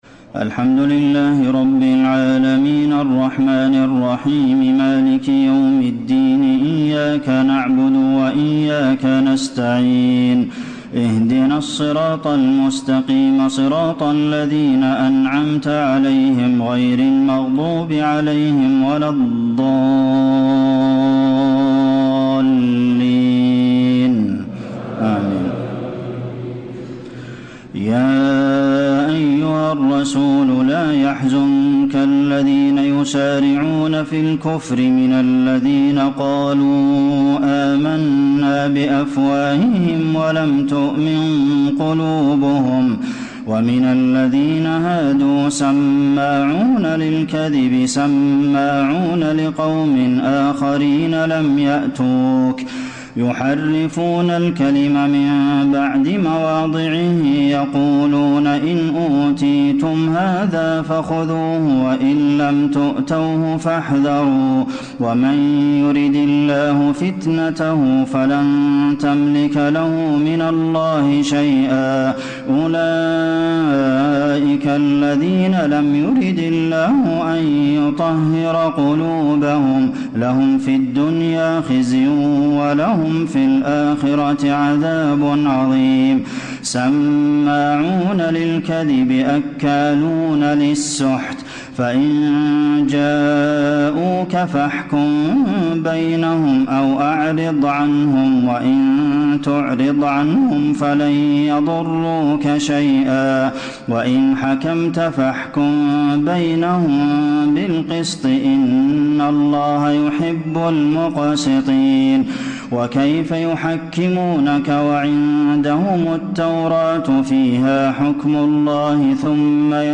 تهجد ليلة 26 رمضان 1434هـ من سورة المائدة (41-81) Tahajjud 26 st night Ramadan 1434H from Surah AlMa'idah > تراويح الحرم النبوي عام 1434 🕌 > التراويح - تلاوات الحرمين